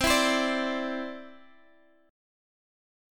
Listen to Cadd9 strummed